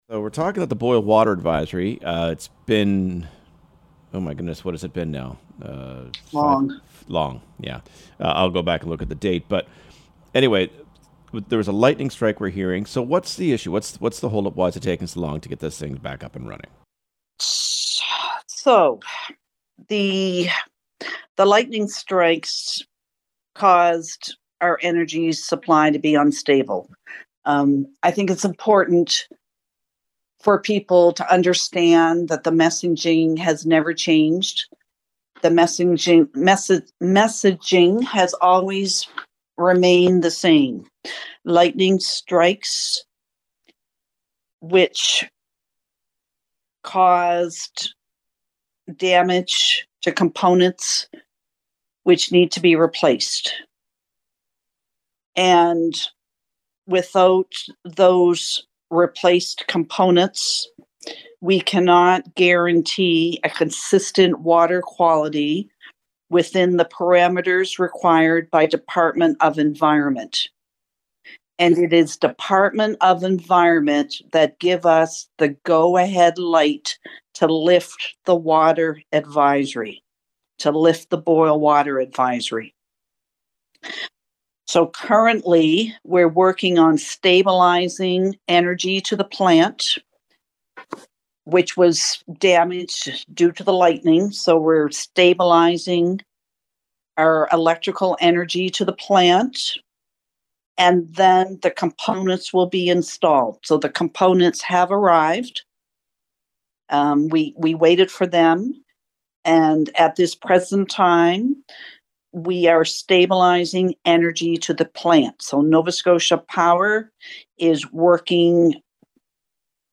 Mayor-Darlene-Norman-Boil-Water-update-full-interview-Sep-7-23.mp3